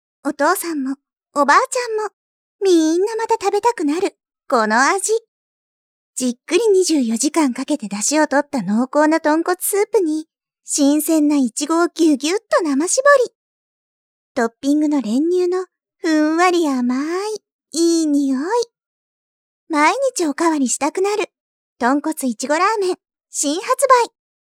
ＣＭ風ナレーション（若いお姉さん声）